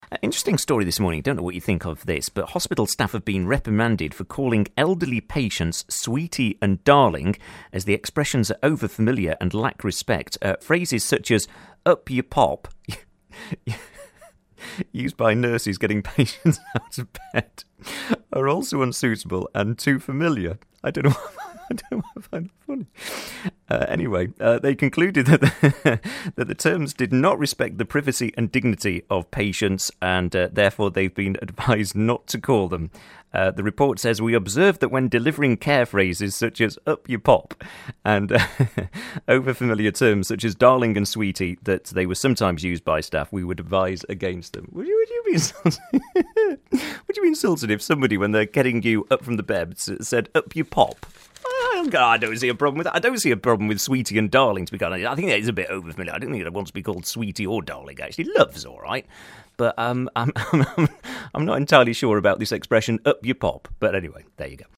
simply laughs his way through something that should have been oh so easy to read...